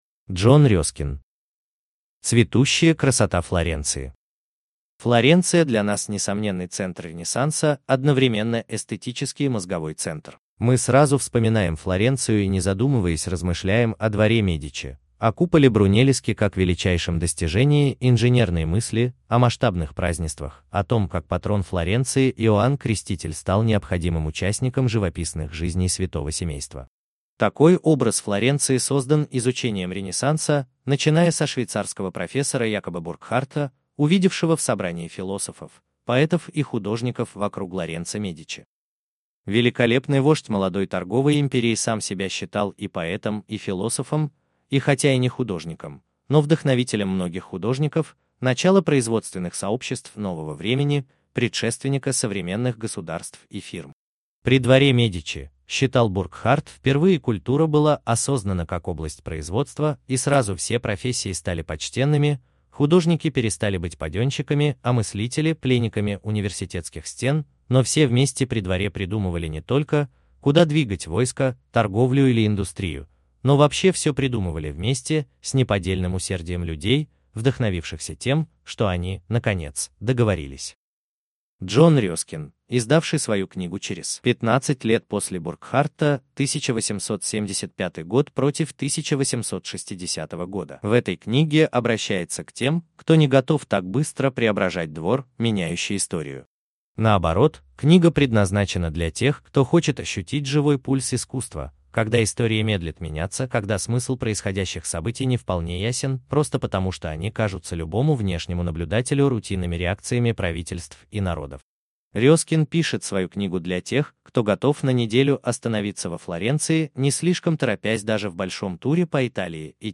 Аудиокнига Прогулки по Флоренции | Библиотека аудиокниг